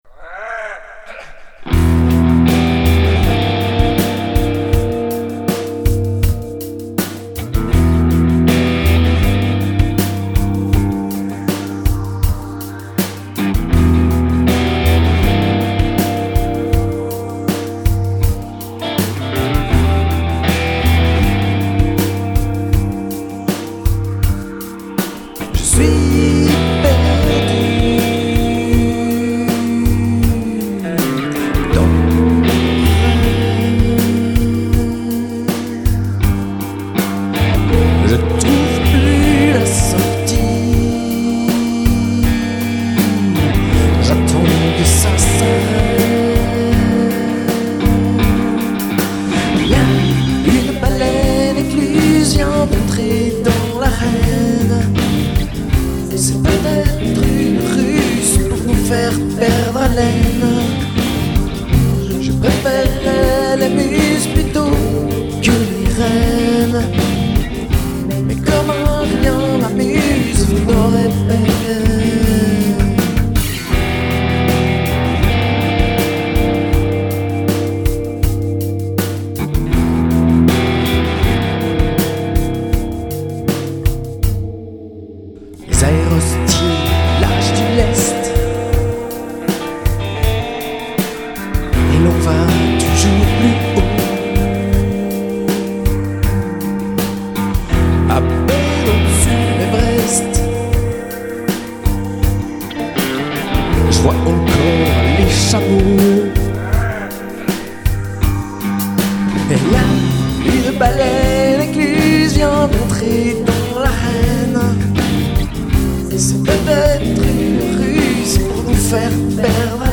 écoutez ce délire bouclé assez rapidement :
Note: ce n'est pas moi qui chante, mais ça aurait pu
Perso, je ne pense pas qu'il s'agisse de vrais amplis quoique j'ai eu un doute pour le solo mais ça sonne un poil artificiel mais dès qu'il y a des delays, c'est forcément plus noyé...
Le son du solo n'est pas mal du tout mais ça manque un poil d'organique pour moi.
Plugin ou pod, son trop en retrait et trop chargé pour se faire une vrai idée
oui c'est vrai que le mix est bien chargé